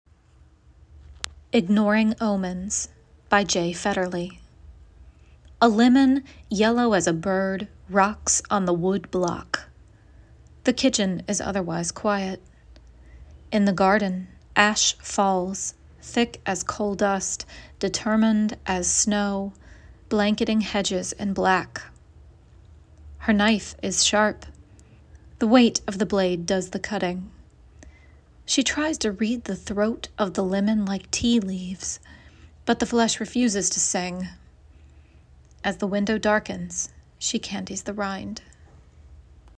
Listen to their reading of "Ignoring Omens"